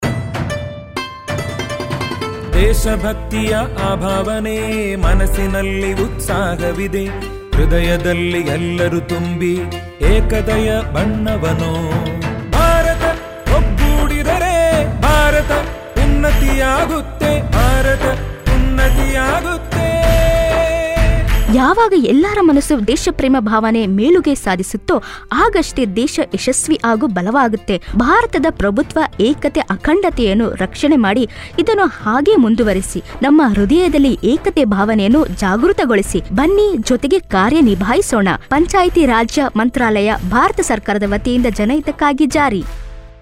Radio Jingle